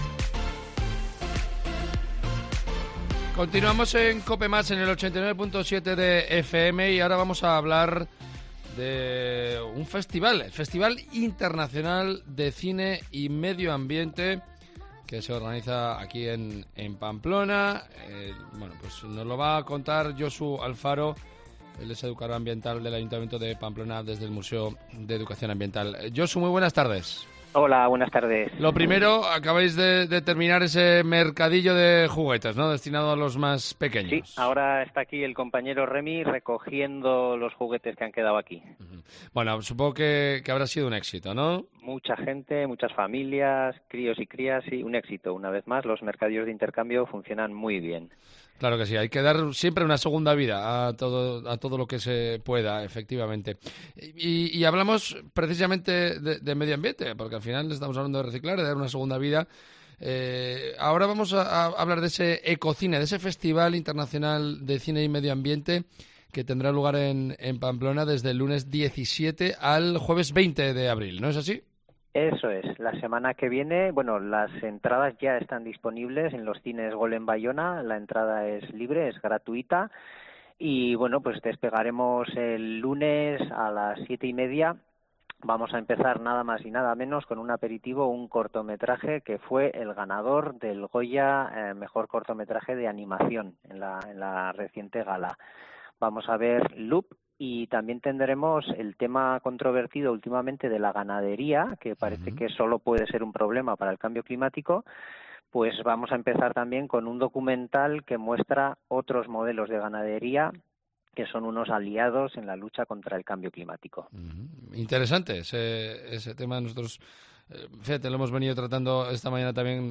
presenta el festival Ecozine que se celebrará del 17 al 20 de abril